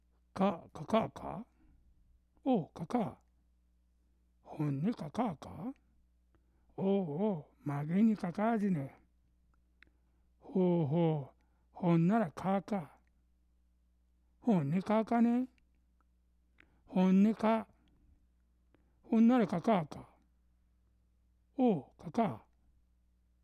声はお一人の声ですが，二人の人の会話です。
この「カカー」も「カカル」の最後の「ル」が脱落し（て，その前の母音がのび）たものです。